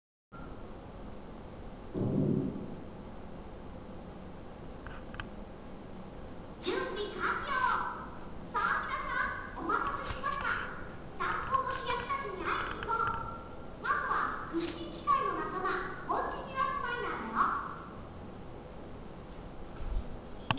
( なんとそこでは渕崎ゆり子さんの声が聴けるらしいのだ )
まず最初のエレベータ内での案内の声はたしかにふっちーっぽい
けどクロベエの声は 『 ホントかよ！？』 ってくらいわからんかったｗ→